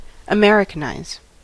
Ääntäminen
IPA : /ə.ˈmɛɹ.ɪ.kɪn.aɪz/